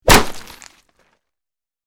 Bullet Hits Body With Wet Impact, X5